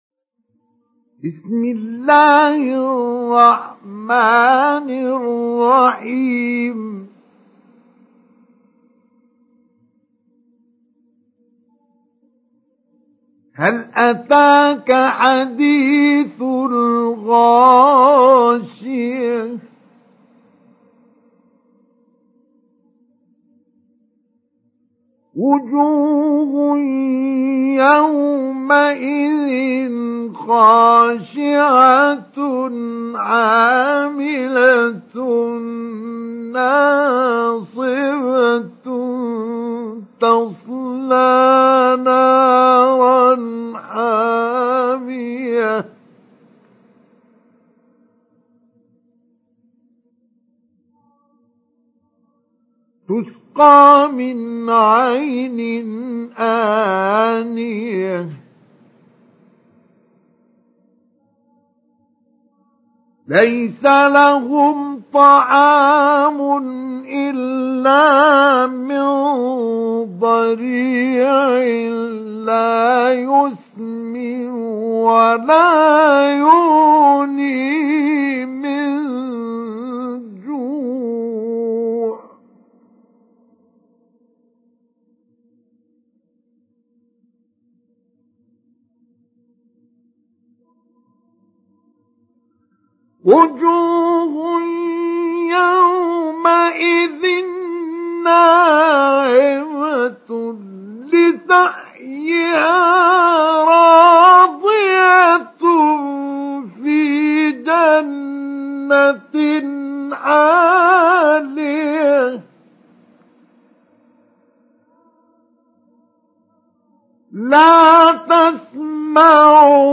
سُورَةُ الغَاشِيَةِ بصوت الشيخ مصطفى اسماعيل